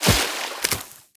Sfx_creature_trivalve_emerge_01.ogg